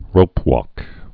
(rōpwôk)